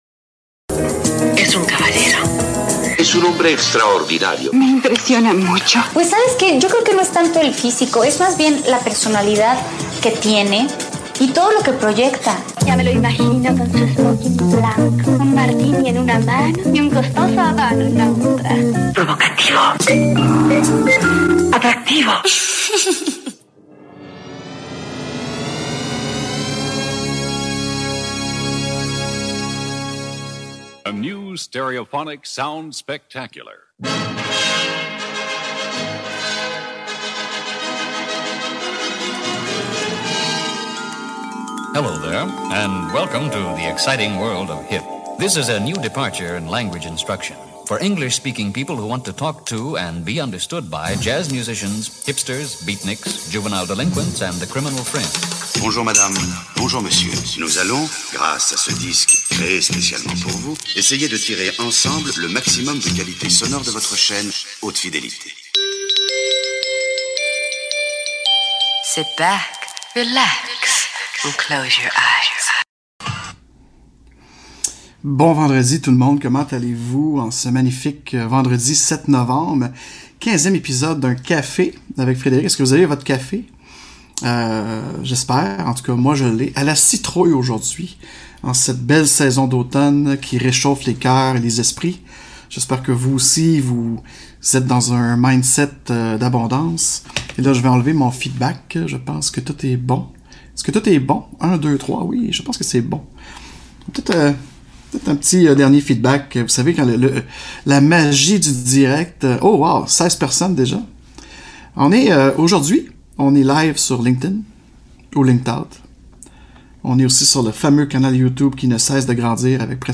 Joins toi à moi avec un bon café, LIVE le Premier Vendredi du mois enrte 9h ou Midi, heure de l’Est.